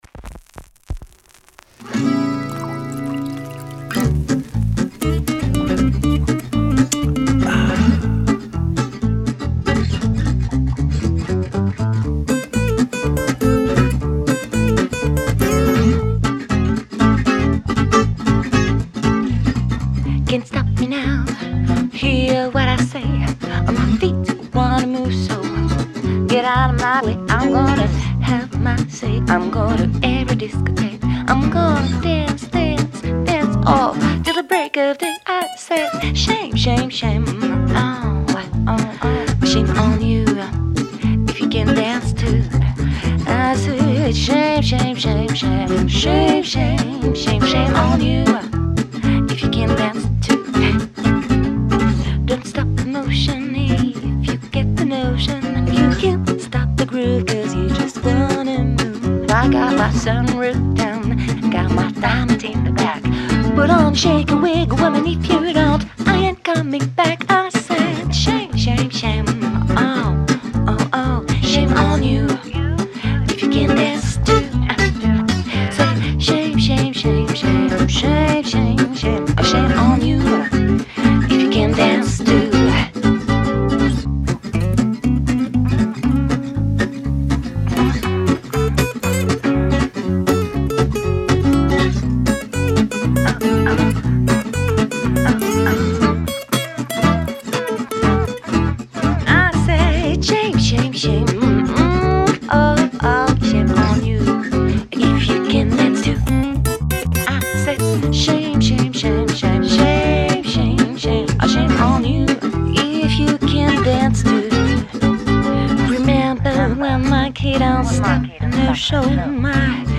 chanteuse